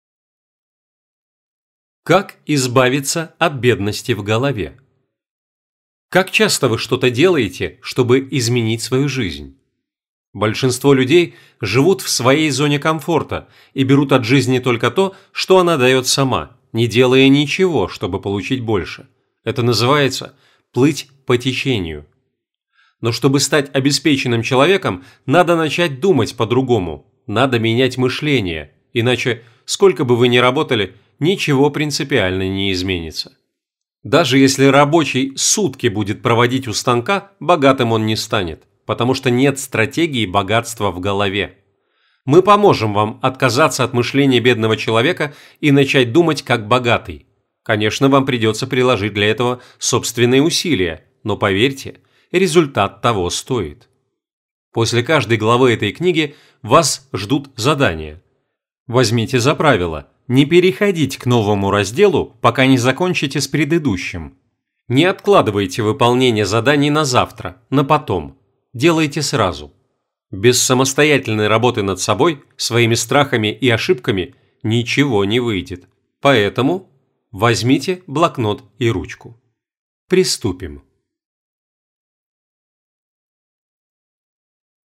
专题解说